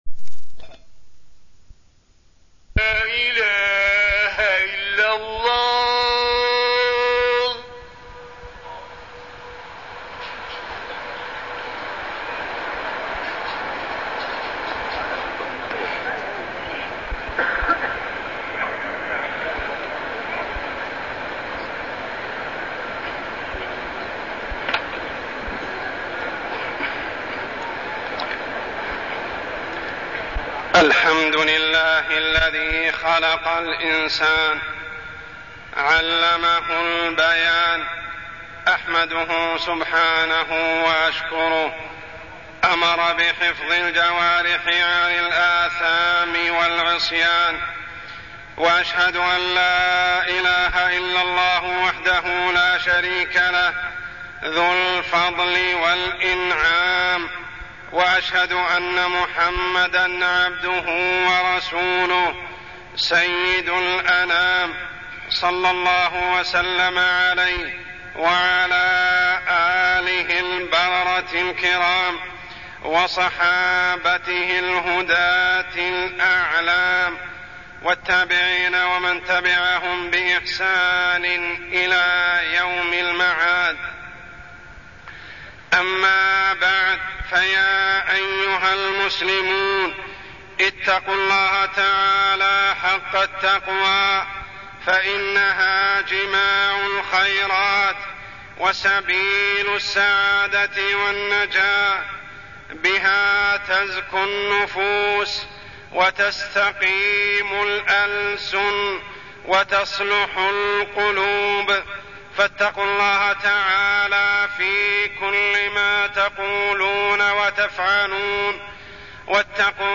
تاريخ النشر ٢٠ رجب ١٤٢٠ هـ المكان: المسجد الحرام الشيخ: عمر السبيل عمر السبيل أدب الحديث